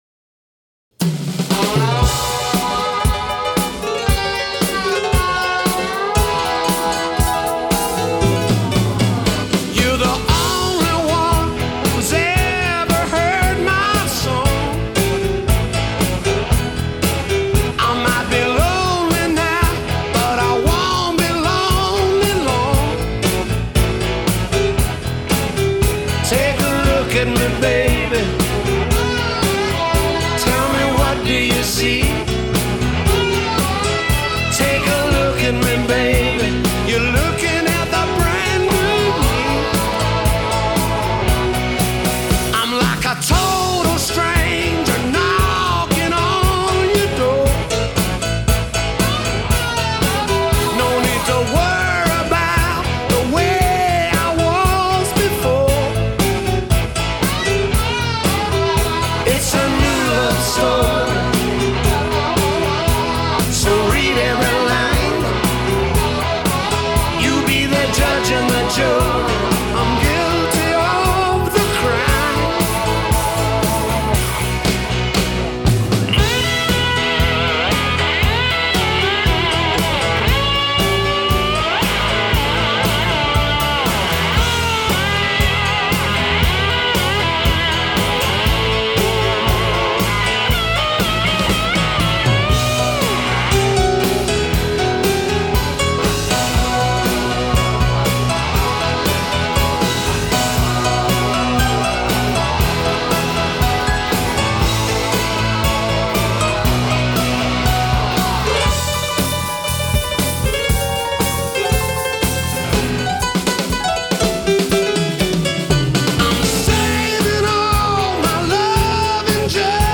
Rock.